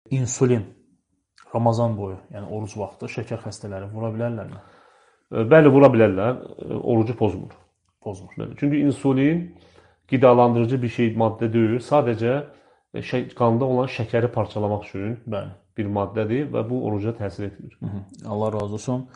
Şeyx Qamət Süleymanov – Sual-cavab 2026